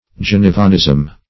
Search Result for " genevanism" : The Collaborative International Dictionary of English v.0.48: Genevanism \Ge*ne"van*ism\, n. [From Geneva, where Calvin resided.]